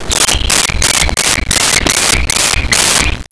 Monkey Chirps
Monkey Chirps.wav